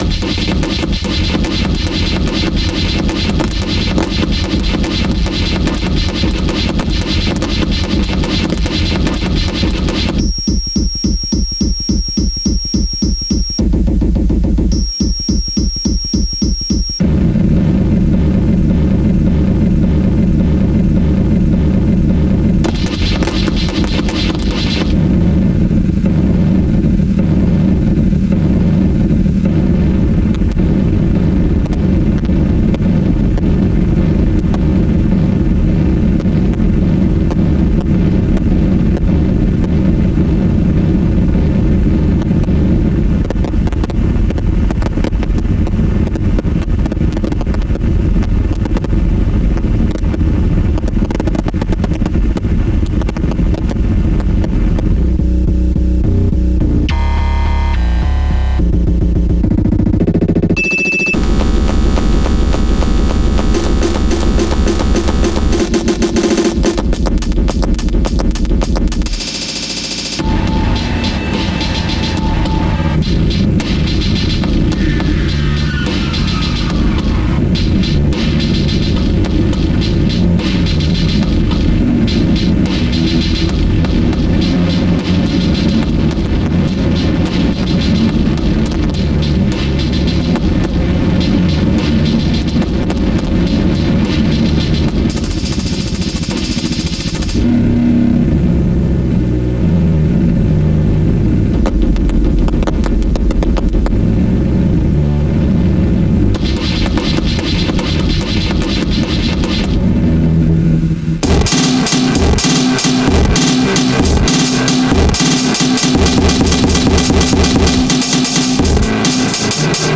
breakcore, shitcore,